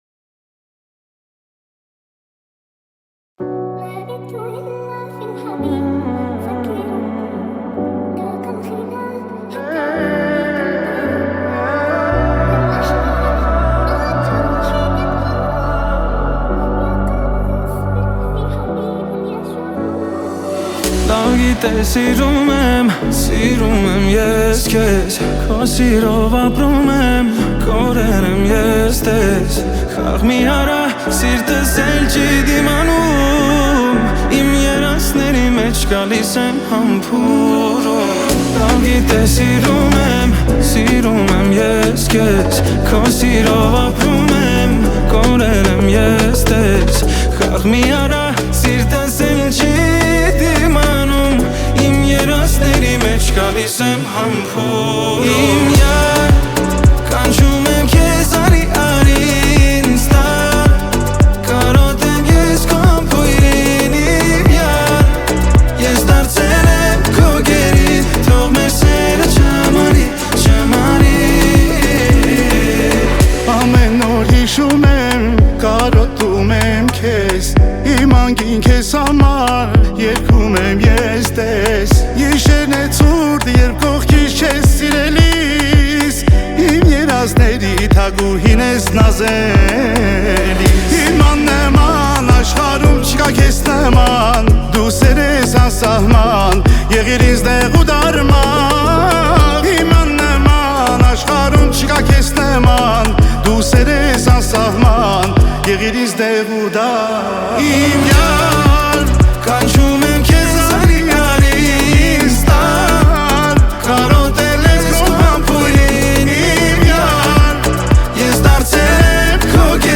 Армянская песня